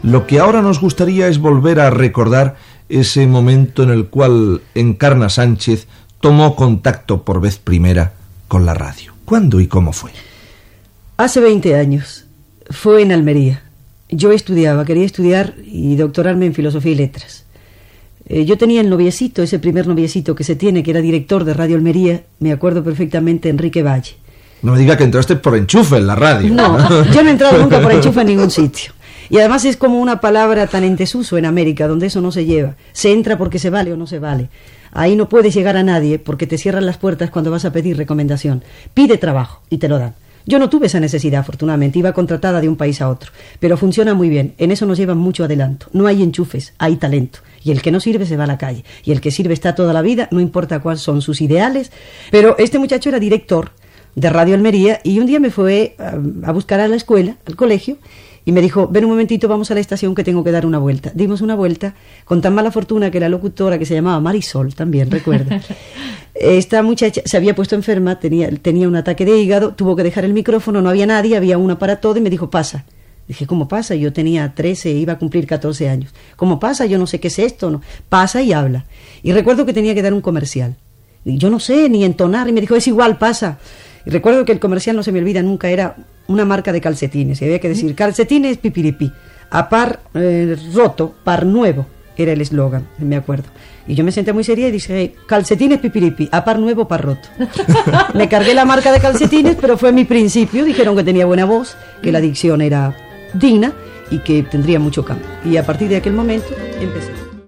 Entrevista a la presentadora Encarna Sánchez que ha retornat de treballar a la ràdio d'Amèrica. Explica els seus inicis a la ràdio d'Almeria
Fragment extret del programa "Audios para recordar" de Radio 5 emès el 22 de març del 2013.